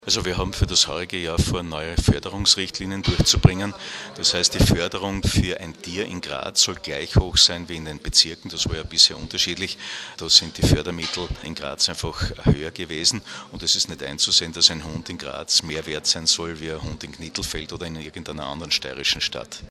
Graz (5. Juni 2013).- Gemeinsam mit dem zuständigen Landesrat Gerhard Kurzmann präsentierte die steirische Tierschutzombudsfrau Barbara Fiala-Köck heute Vormittag (05.06.2013) im Medienzentrum Steiermark ihren aktuellen Tätigkeitsbericht.